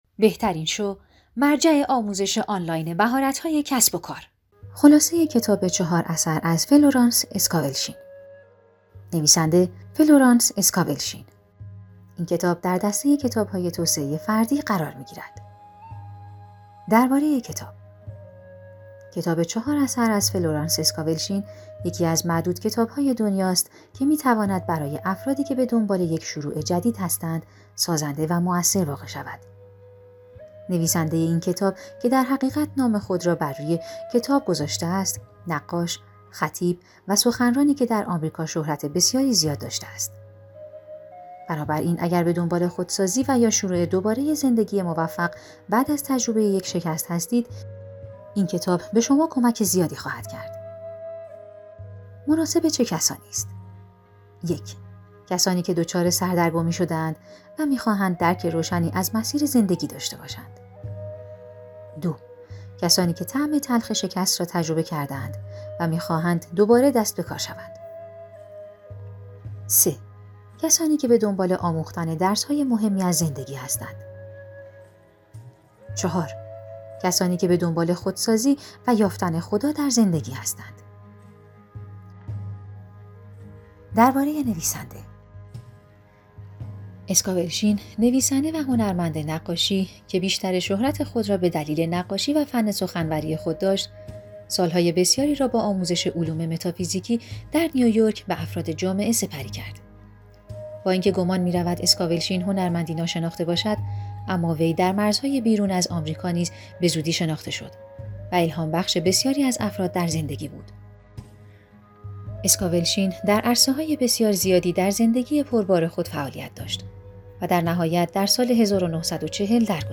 کتاب صوتی موجود است